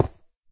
stone1.ogg